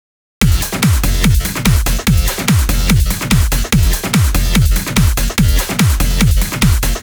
少しわかりにくいのでアシッド音をぬいた音源で確認しましょう。
【ビートのみ】
なんか低域がムワッとしてるといった感じがします。